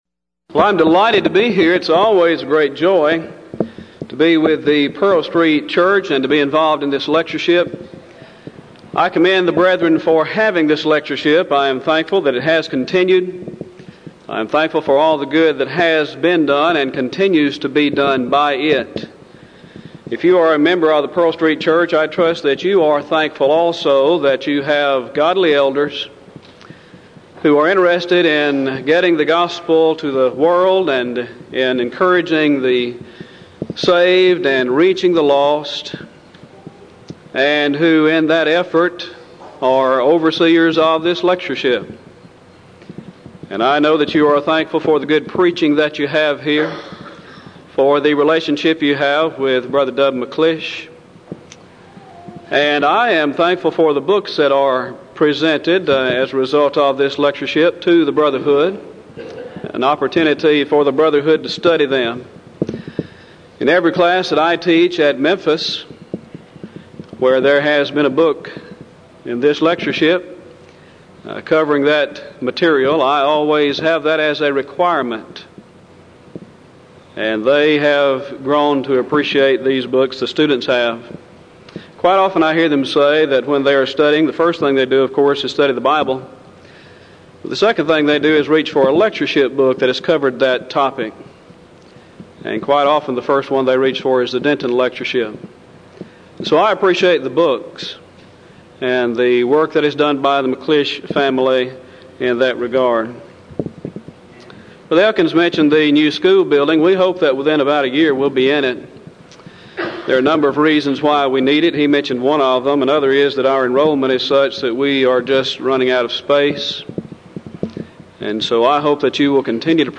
Event: 1996 Denton Lectures
lecture